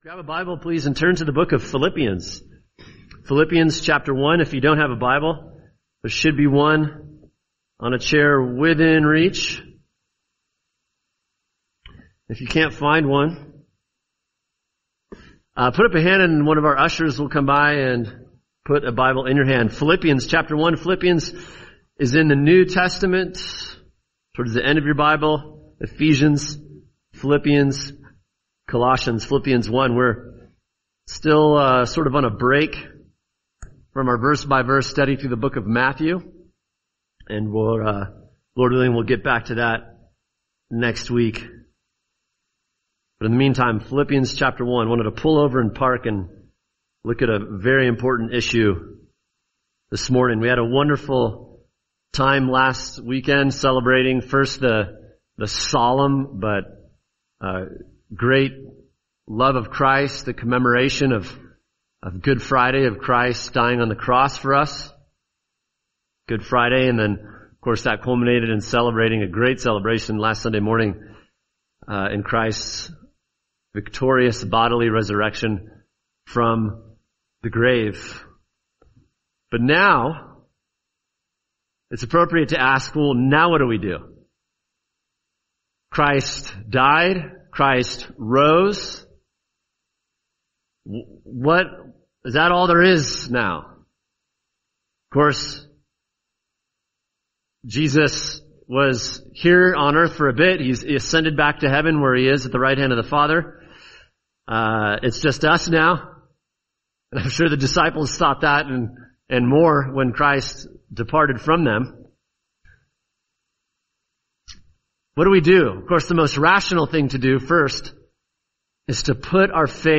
[sermon] Philippians 1:2-11 Strategies for Living Among Imperfect People | Cornerstone Church - Jackson Hole